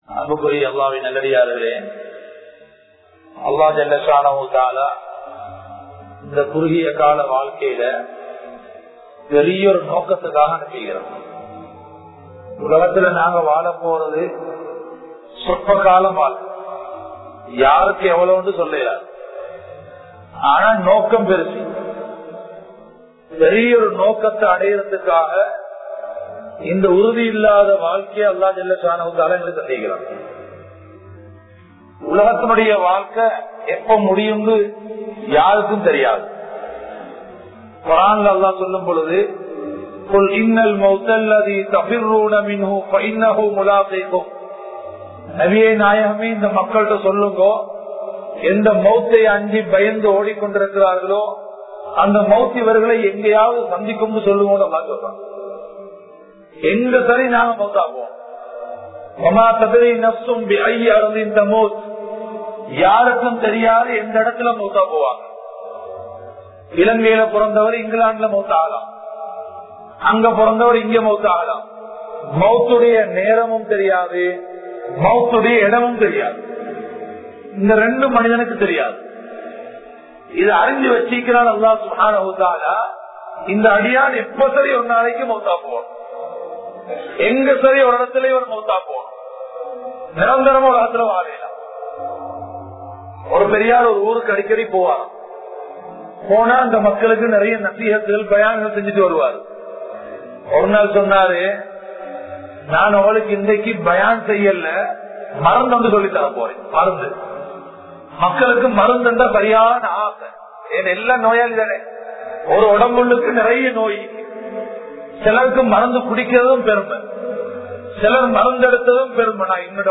Dawathudaiya Ulaippin Nokkam(தஃவத்துடைய உழைப்பின் நோக்கம்) | Audio Bayans | All Ceylon Muslim Youth Community | Addalaichenai
Colombo 03, Kollupitty Jumua Masjith